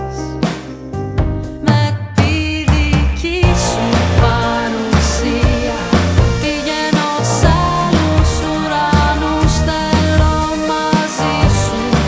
Ένα τραγούδι γεμάτο συναίσθημα και δυναμισμό.